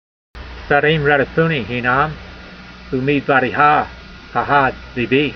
It seems it is pronounced as if were merely a sheva without a yud following it.